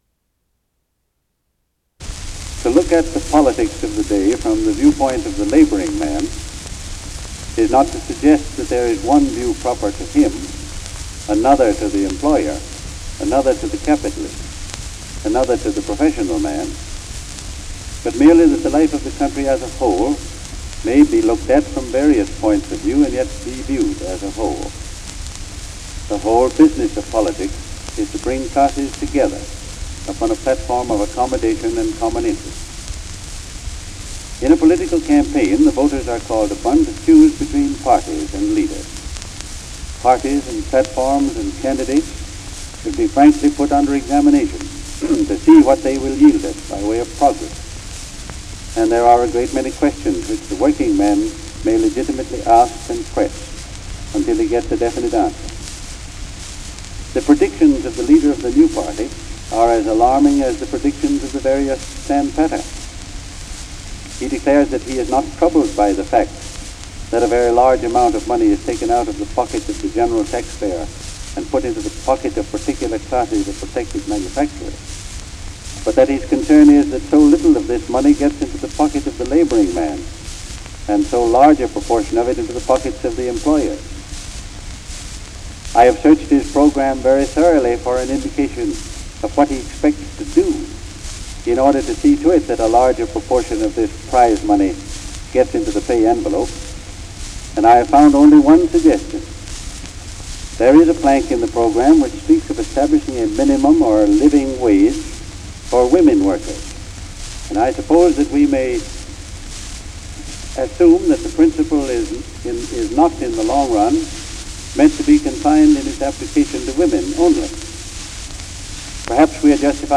Campaign speech on the interests of labor
Recorded by Victor Talking Machine, 1912.